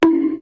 Cartoon-bonk Sound Effect Download: Instant Soundboard Button